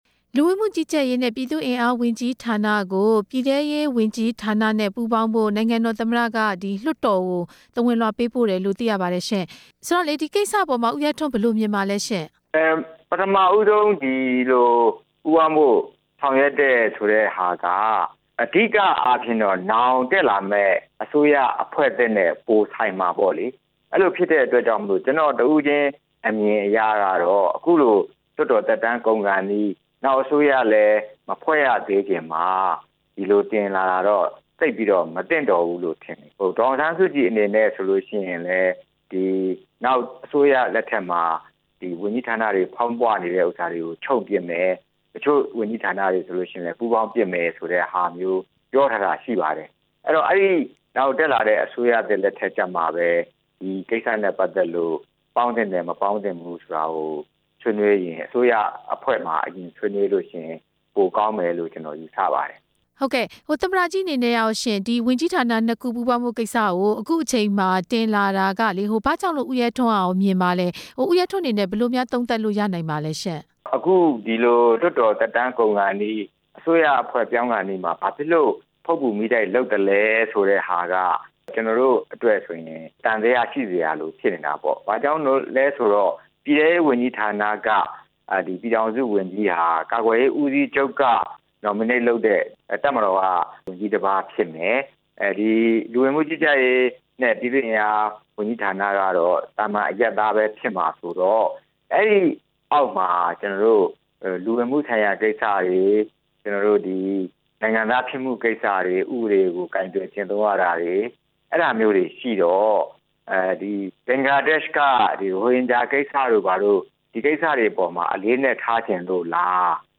လဝက ဝန်ကြီးဌာနကို ပြည်ထဲရေးဌာနအောက် ပြောင်းရွှေ့ရေး၊ ဦးရဲထွန်းနဲ့ မေးမြန်းချက်